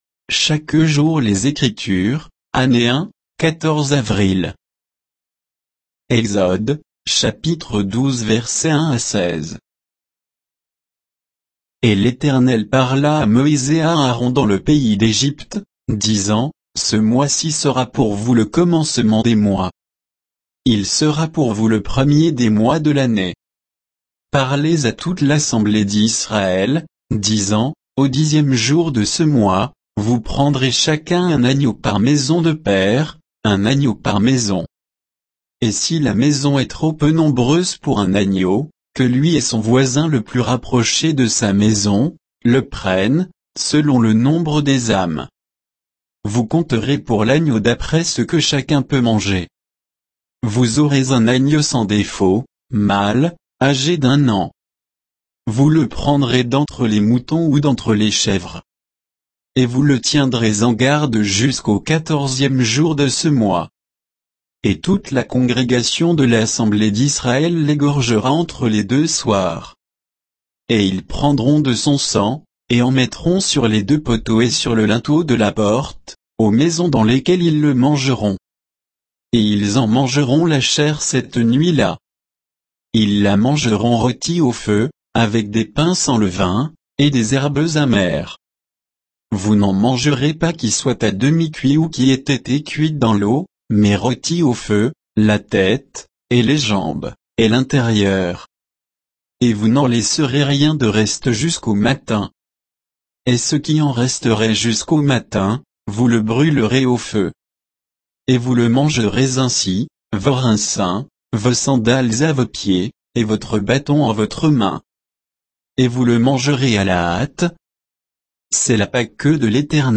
Méditation quoditienne de Chaque jour les Écritures sur Exode 12